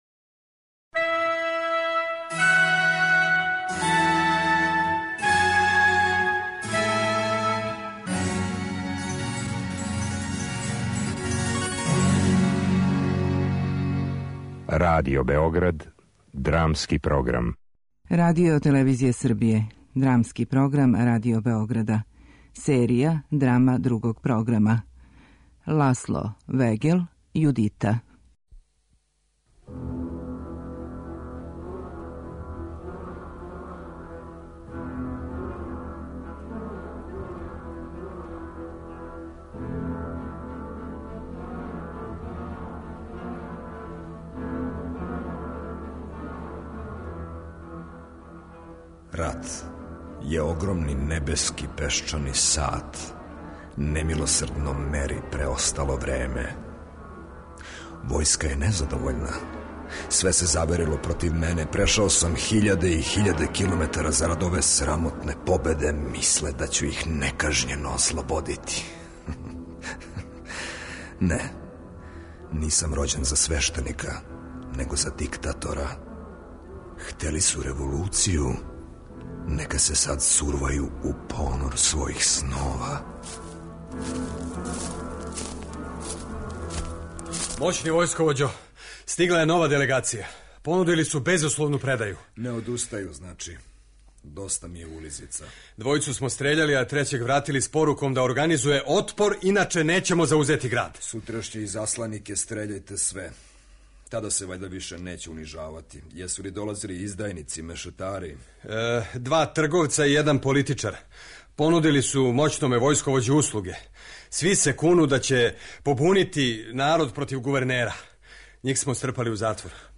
Драмски програм: Драма